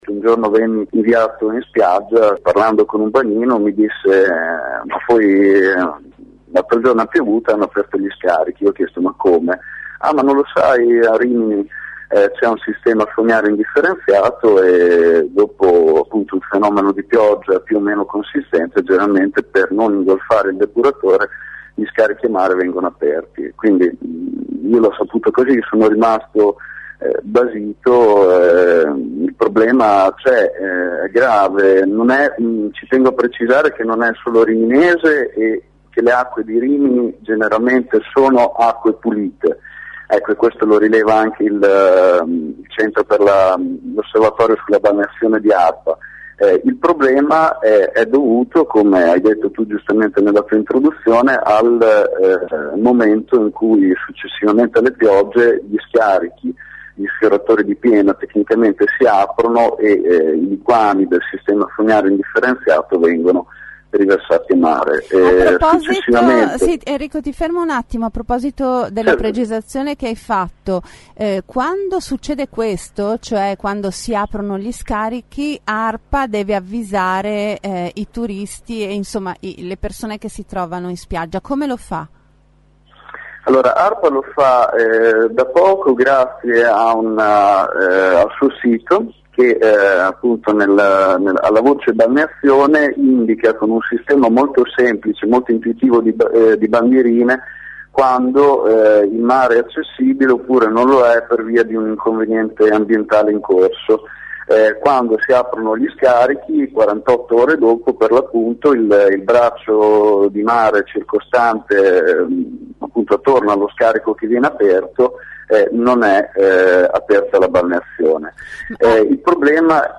ha raccontato ai nostri microfoni questa situazione che risale agli anni 70.